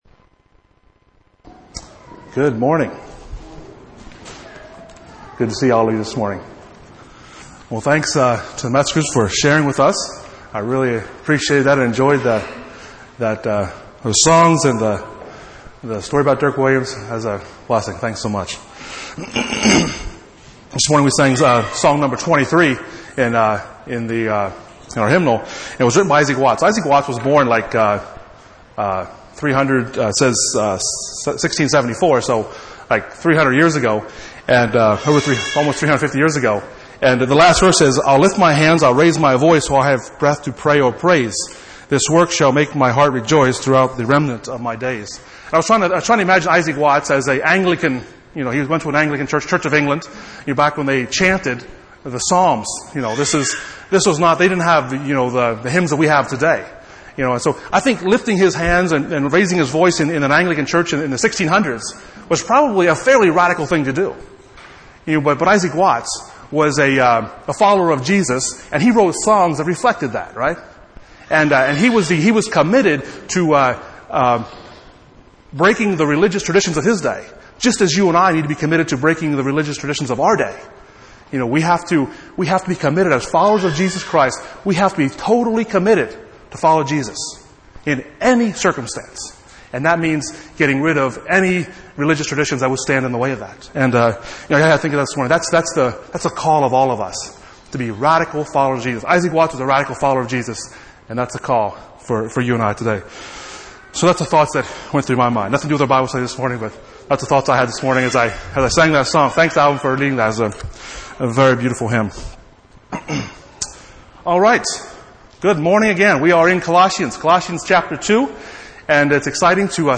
Passage: Colossians 1:1-7 Service Type: Sunday Morning